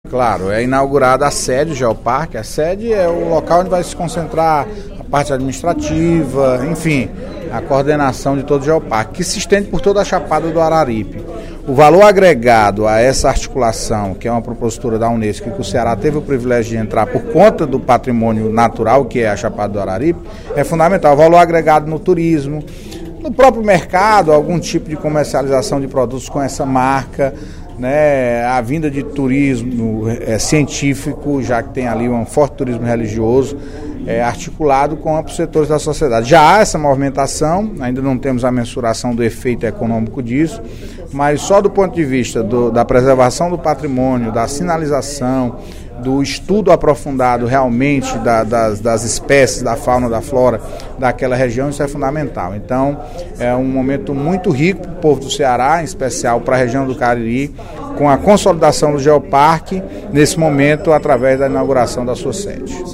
O deputado Antonio Carlos (PT) destacou, na sessão plenária desta sexta-feira (16/03), a inauguração da sede do Geopark Araripe, localizado no sul do Estado, na região do Cariri. O líder do Governo ressaltou os benefícios que o equipamento está gerando não só para a ciência, mas para o desenvolvimento do setor turístico na região.